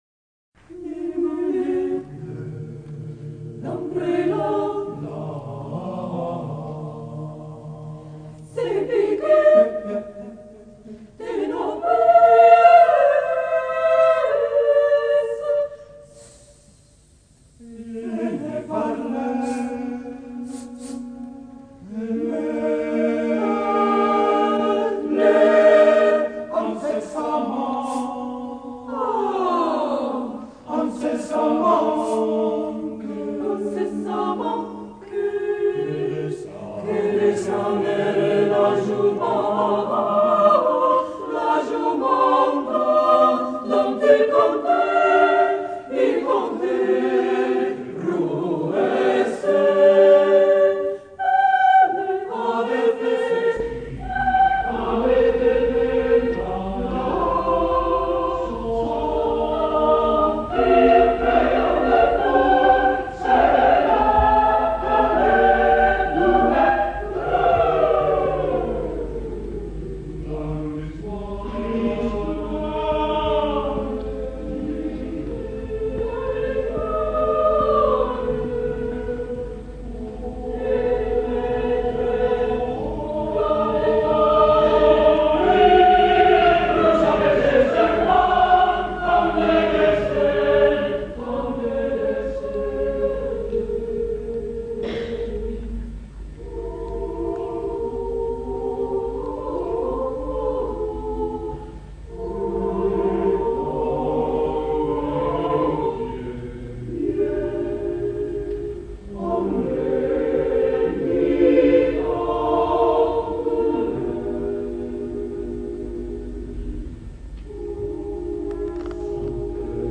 Number of voices: 4vv Voicing: SATB Genre: Secular, Madrigal